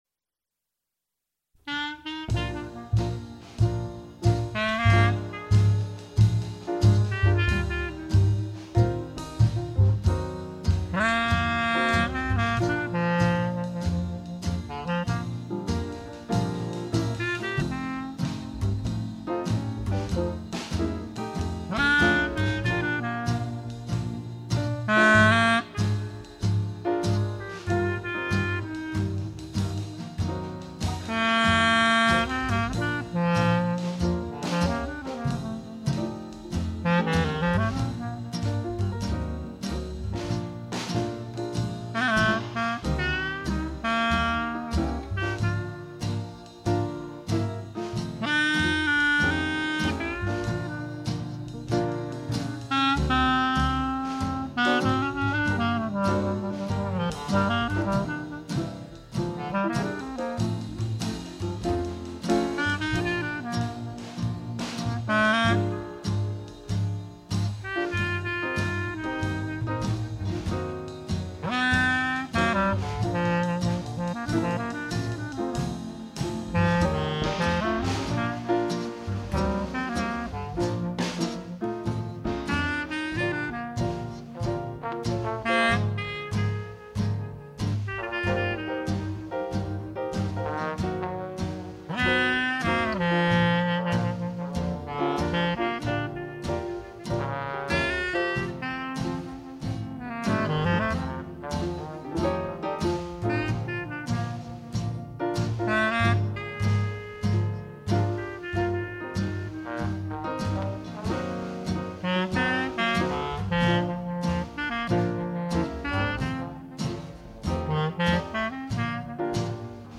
clarinet.